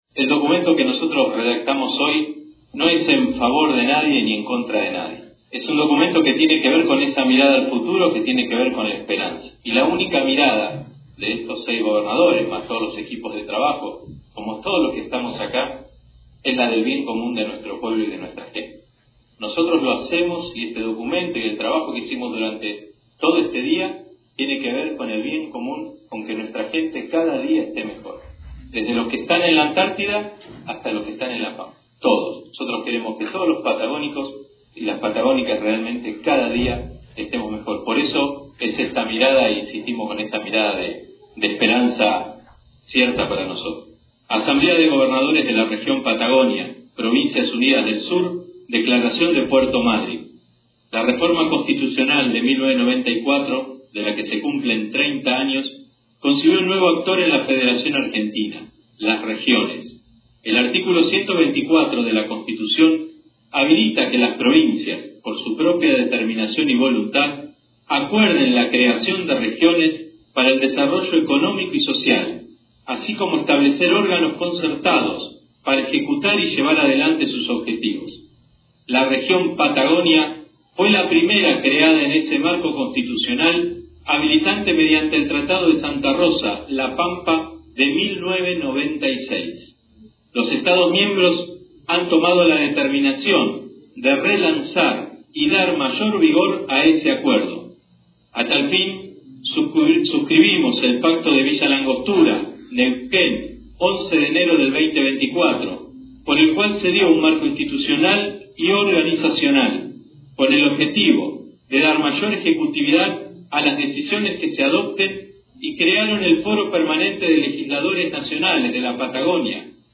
El Gobernador de Tierra del Fuego AeIAS, Gustavo Melella, participó del 2° Encuentro de Gobernadores de la Región Patagónica que se realizó este jueves en Puerto Madryn.